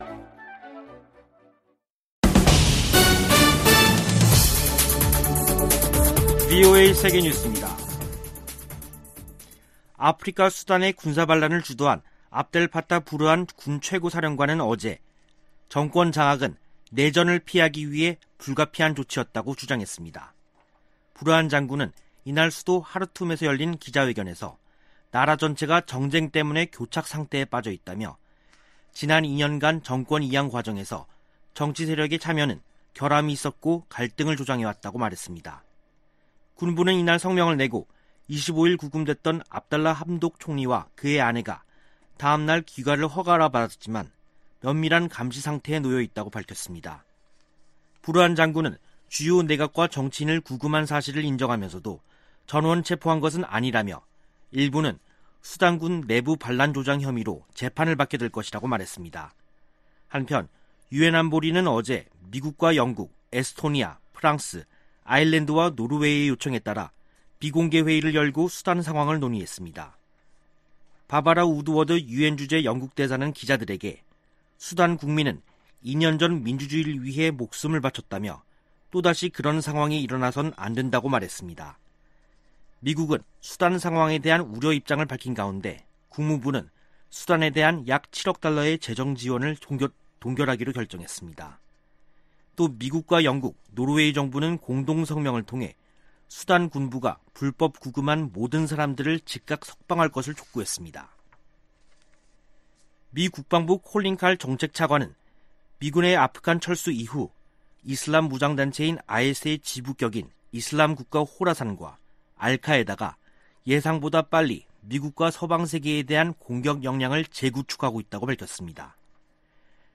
VOA 한국어 간판 뉴스 프로그램 '뉴스 투데이', 2021년 10월 27일 3부 방송입니다. 대북 접근법 수행 과정에 미국과 한국의 관점이 다를 수도 있다고 제이크 설리번 미 국가안보 보좌관이 밝혔습니다. 미 국무부가 국제 해킹 대응 조직을 신설합니다. 캐나다 인권단체가 한국과 미국에 이어 세 번째로 제3국 내 탈북 난민 수용 시범 프로그램을 시작합니다.